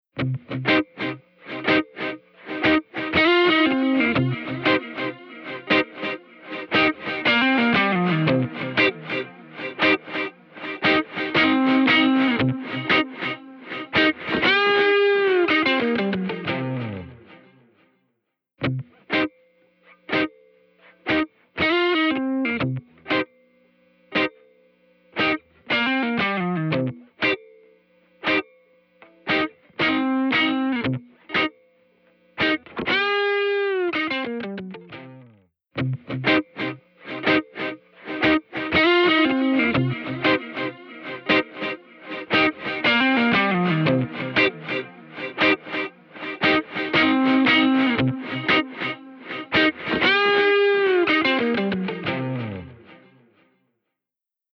多彩なキャラクターと高度な制御を備えたポリリズム・フィードバックディレイ
Recirculate | Electric Guitar | Preset: Backbeat